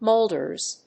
/ˈmoldɝz(米国英語), ˈməʊldɜ:z(英国英語)/